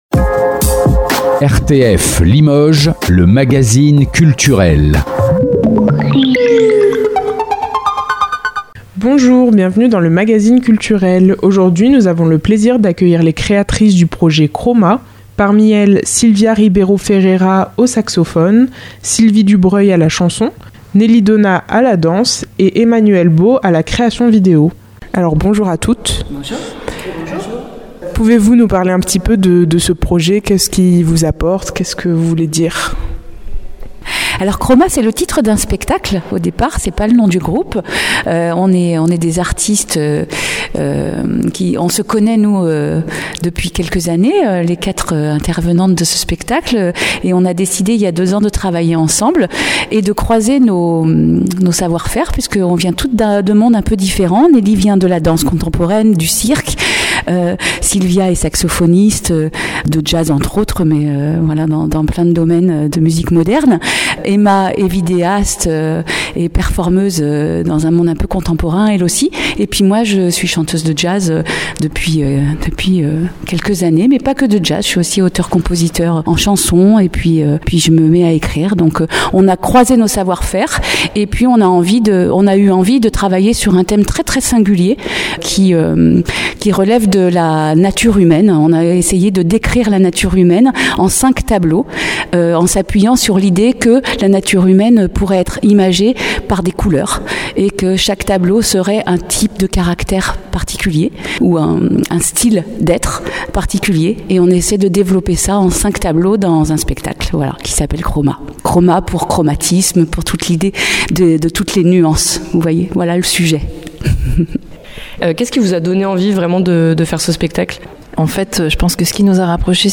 interview Chroma novembre 2024 - Radio RTF Limoges